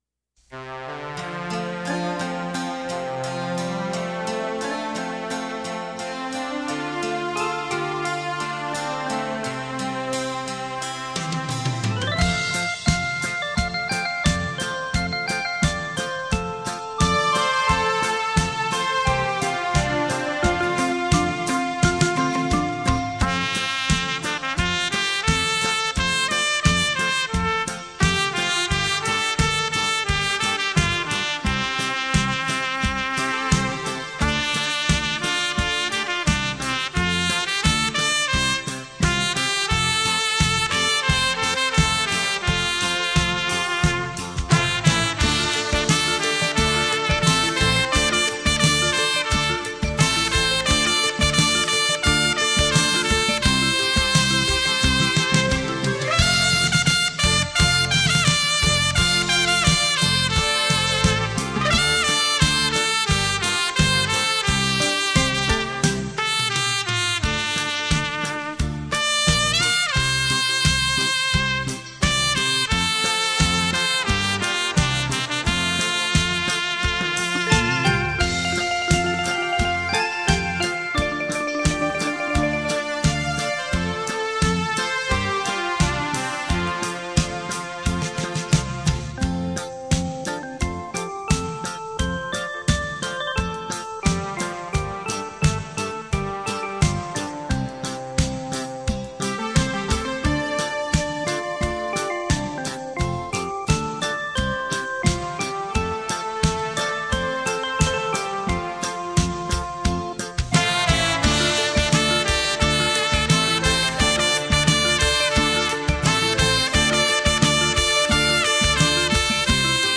优美的演奏，值得您细细品味收藏